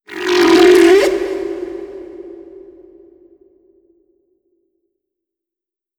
khloCritter_Male34-Verb.wav